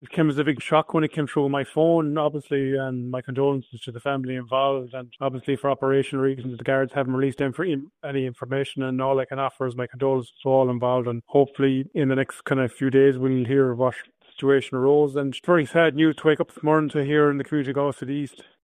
Speaking to Galway Bay FM, local Fine Gael councillor Shane Forde says it’s very sad news: